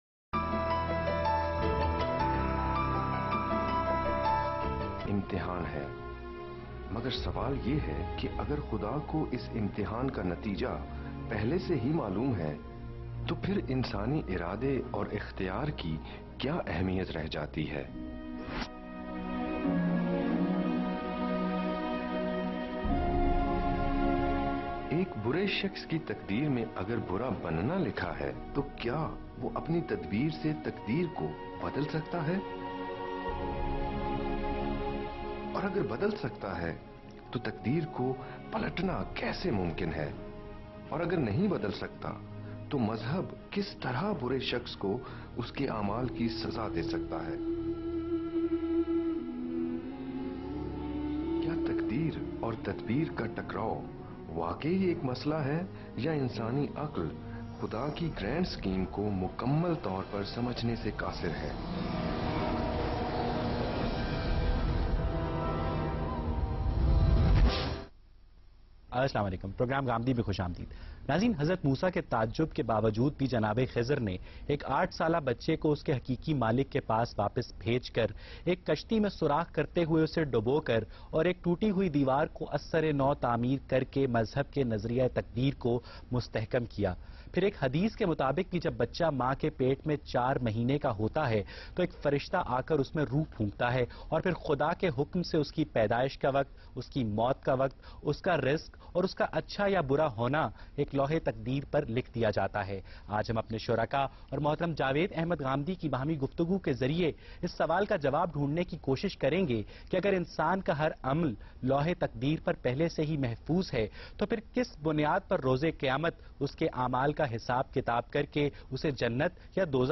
Category: TV Programs / Geo Tv / Ghamidi /
Questions and Answers on the topic “ The Determinism or Free will?” by today’s youth and satisfying answers by Javed Ahmad Ghamidi.